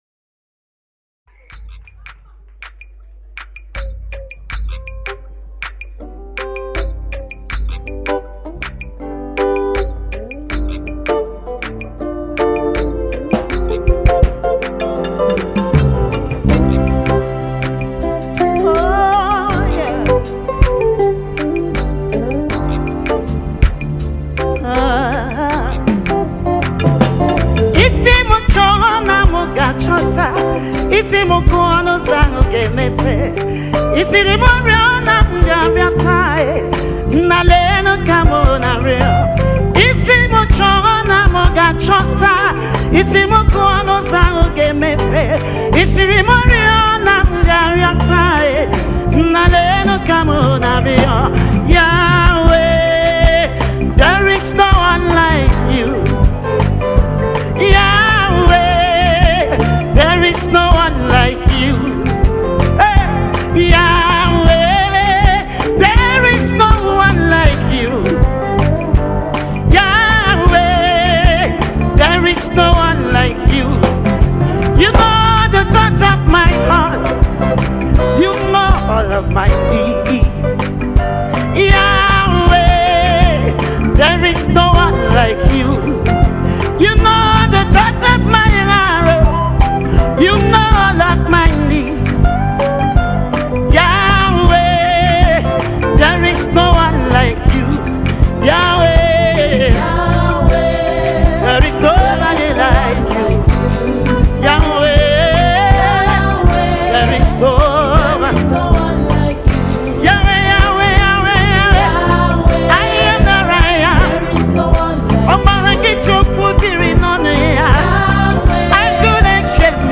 Nigerian gospel music minister